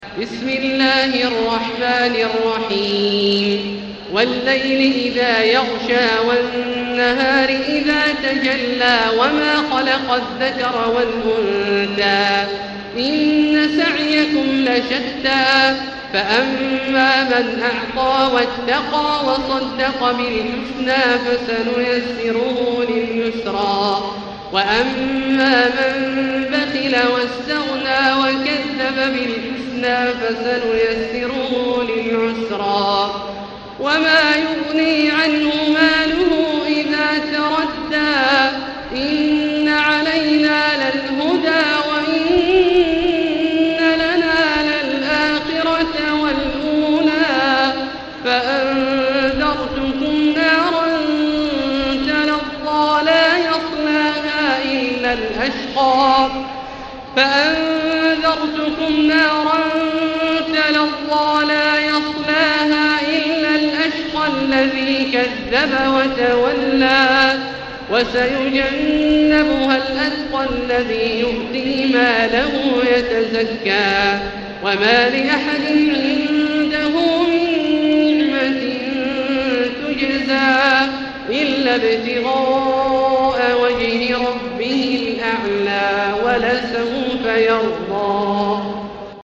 المكان: المسجد الحرام الشيخ: فضيلة الشيخ عبدالله الجهني فضيلة الشيخ عبدالله الجهني الليل